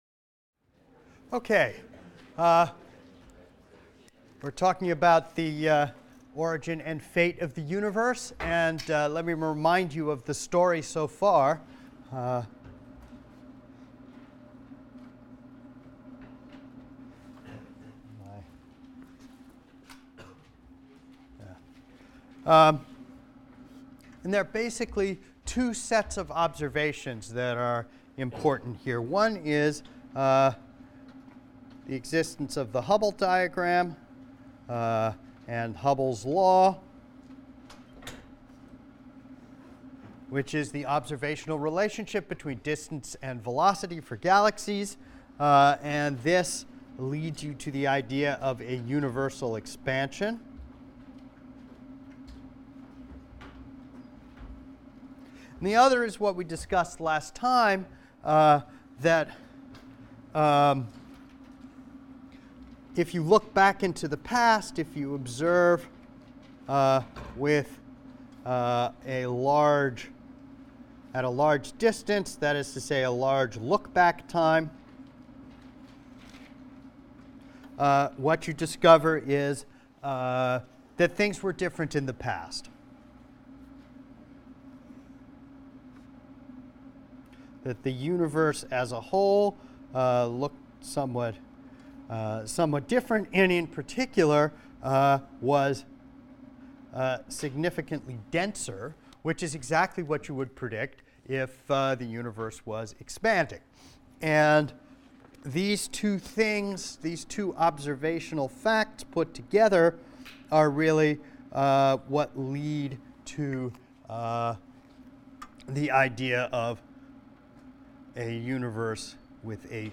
ASTR 160 - Lecture 19 - Omega and the End of the Universe | Open Yale Courses